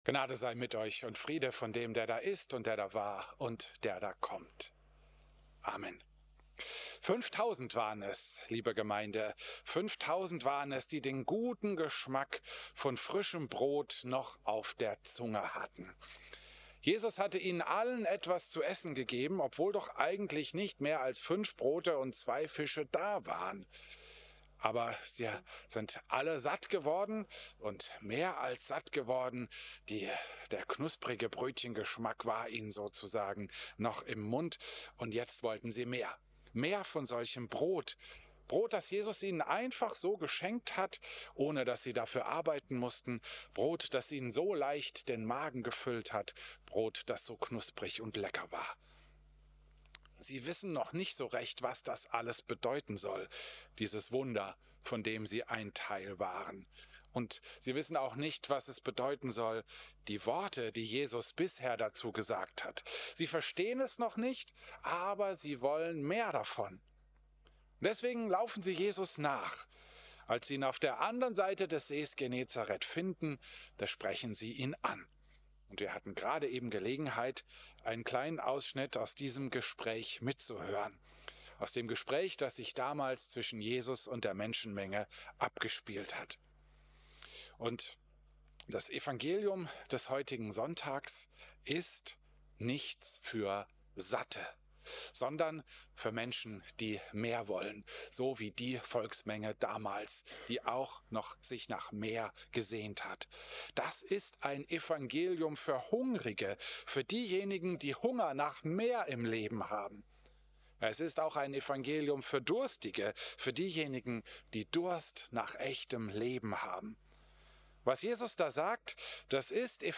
Christus-Pavillon Volkenroda, 3. August 2025